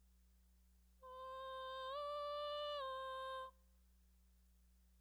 7) Simulating bone-transmission sound
I think it places somewhere between air and bone-conducted sounds.